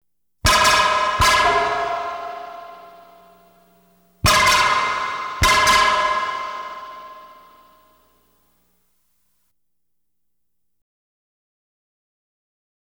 Light Beam Hits Sound Effect
light-beam-hits.wav